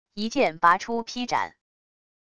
一剑拔出劈斩wav音频